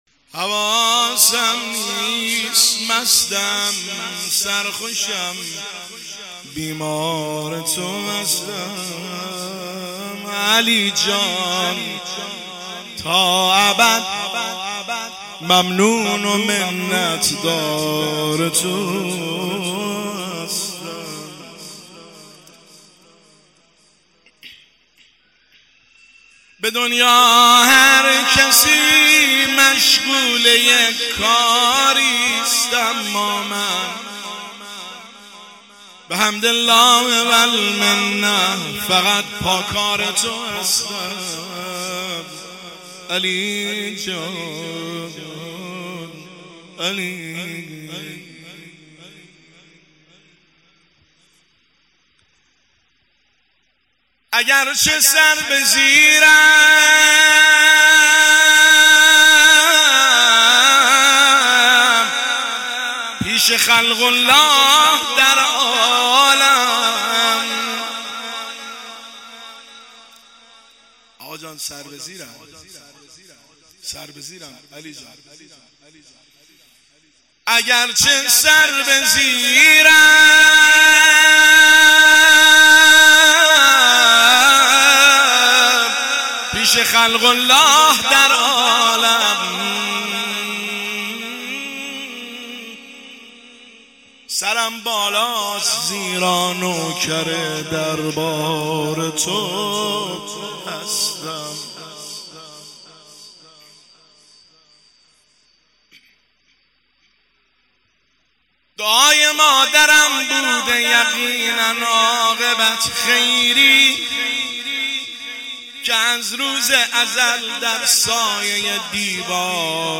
شعرخوانی شب شهادت حضرت مسلم 1403
هیئت شاه کربلا تنکابن